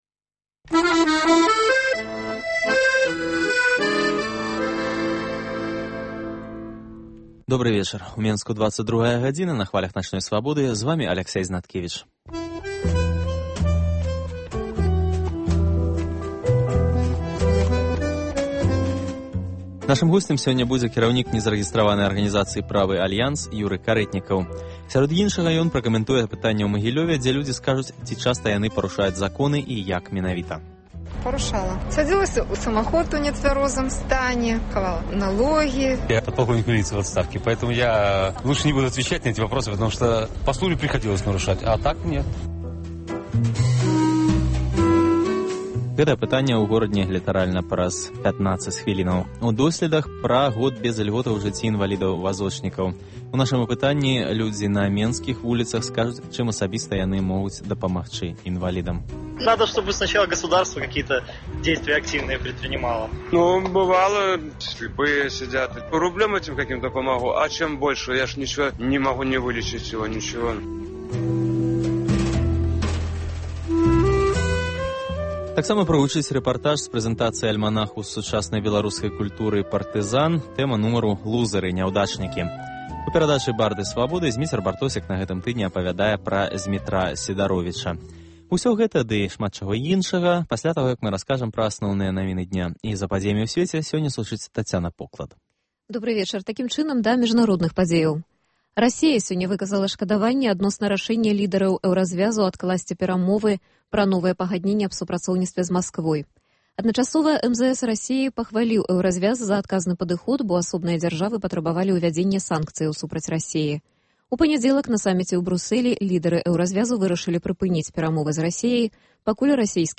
* У досьледах – пра год бяз ільготаў у жыцьці інвалідаў-вазочнікаў. У нашым апытаньні людзі на менскіх вуліцах скажуць, чым асабіста яны могуць дапамагчы інвалідам. * Рэпартаж з прэзэнтацыі альманаха сучаснай беларускай культуры "pARTisan".